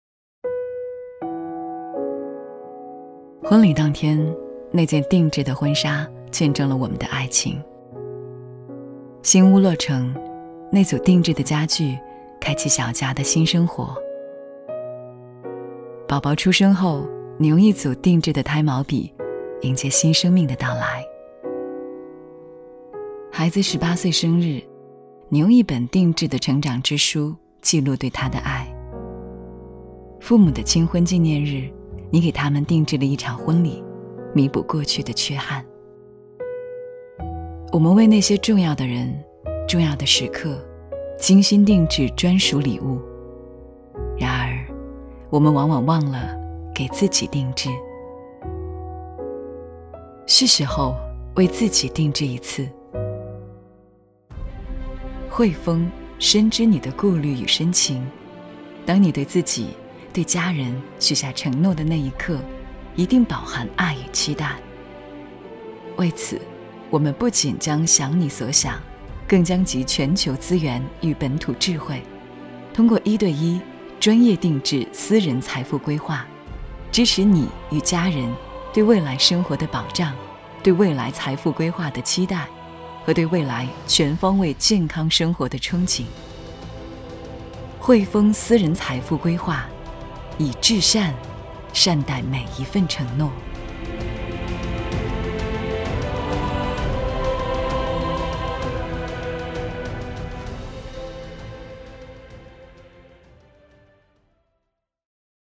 2 女国190_广告_银行_汇丰银行_知性 女国190
女国190_广告_银行_汇丰银行_知性.mp3